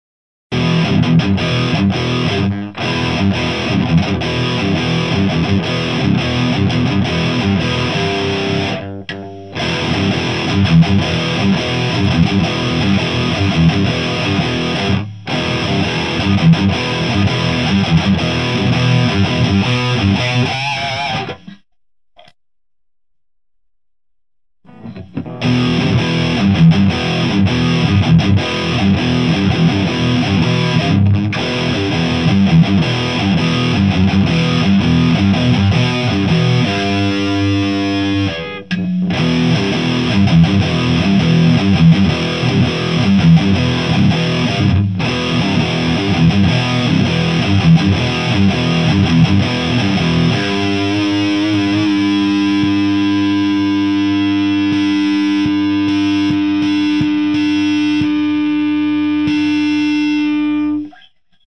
18VにしたEMG 81PA-2のサンプルもアップ！
ギターはFERNANDES MG-120Xです。
MTRはMRS-8を使いました。マイクはSM57 PG57
JCM2000 DSL100
GAIN7 Bass8 Middle10 Treble7
ULTRA GAIN